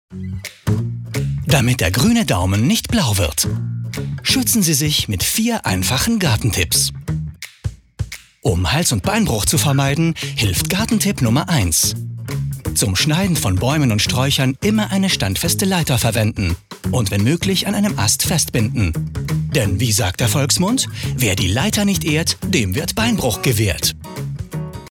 Werbung Hochdeutsch (CH)
Schauspieler mit breitem Einsatzspektrum.